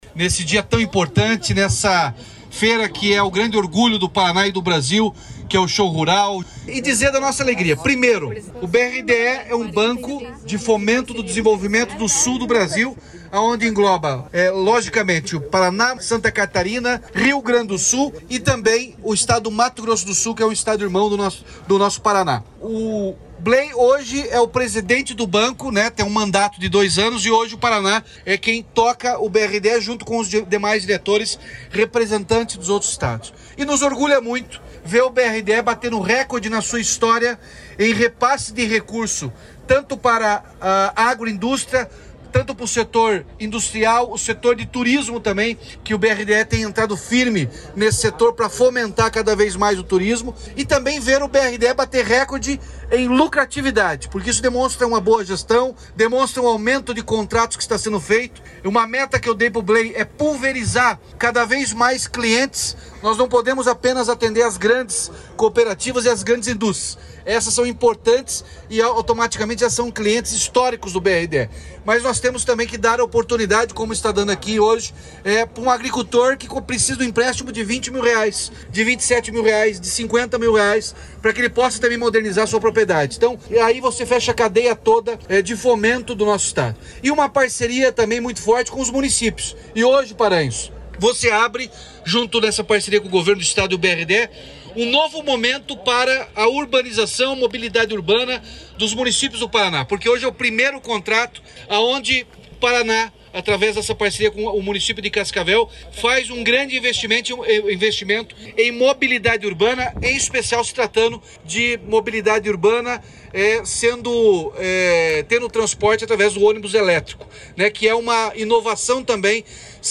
Sonora do governador Ratinho Junior sobre os R$ 240 milhões liberados pelo BRDE para cooperativas, empresas e prefeitura
RATINHO JUNIOR - BRDE SHOW RURAL.mp3